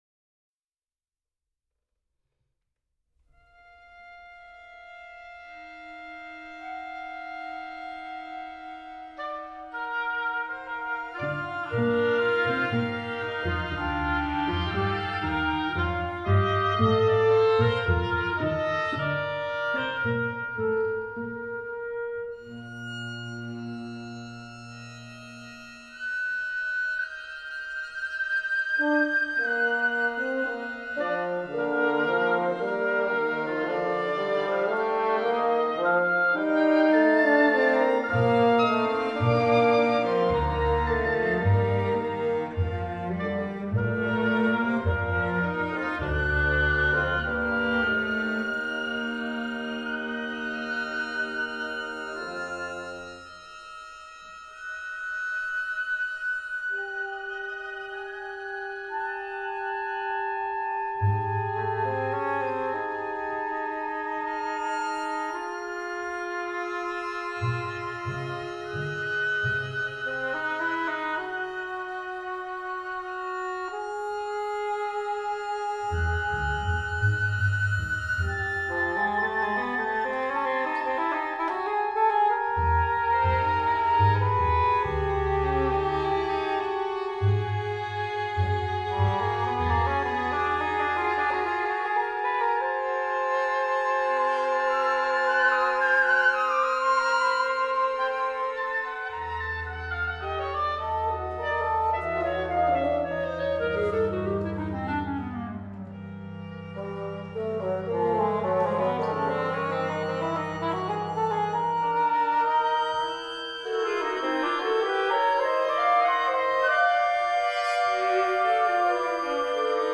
Work for English horn and chamber ensemble